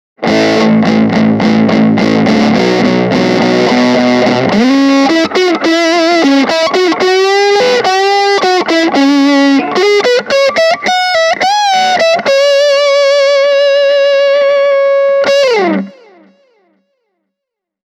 JTM1C on hyvin kermainen ja lämmin, kun taas JMP:llä on tarjolla selkeästi enemmän säröä ja soundi on rouheampi.
Marshall JMP1C – Hamer Studio Custom/gain täysillä